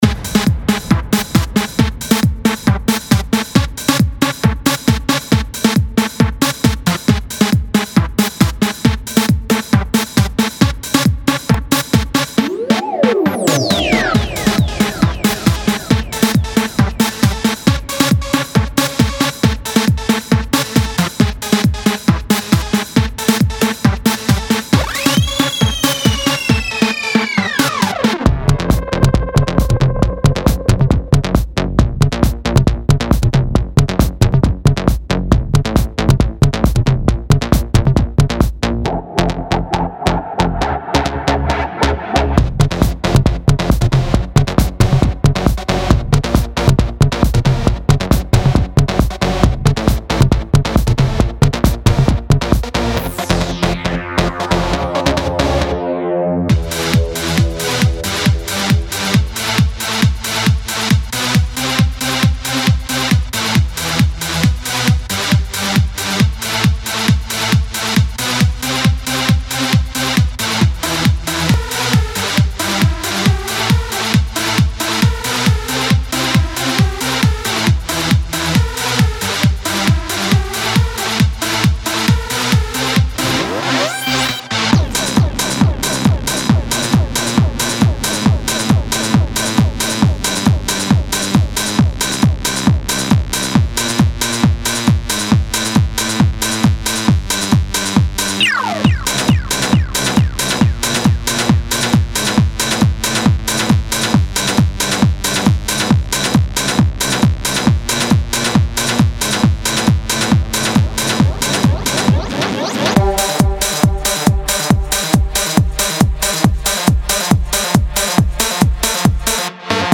uplifting hooks and melodies.
Synth and Lead Loops ONLY.